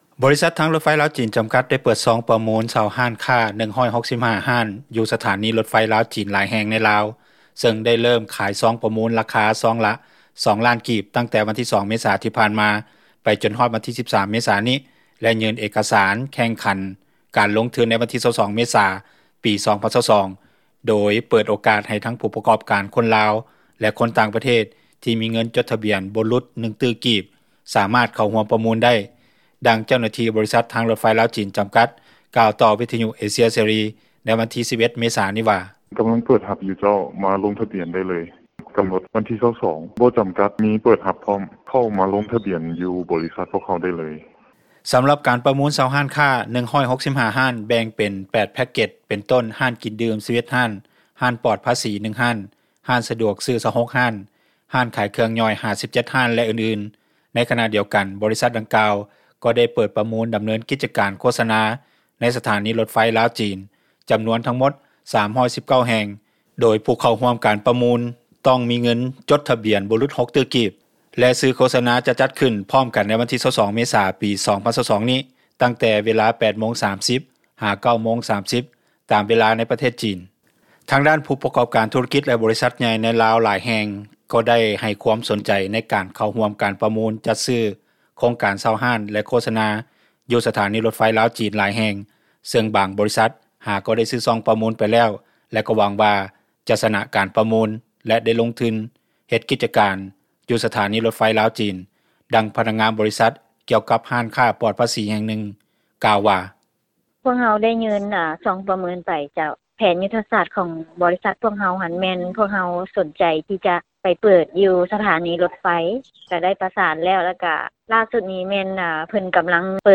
ດັ່ງຍານາງກ່າວວ່າ:
ດັ່ງຜູ້ປະກອບການ ຮ້ານຂາຍເຄື່ອງຫຍ່ອຍທ່ານນຶ່ງກ່າວວ່າ: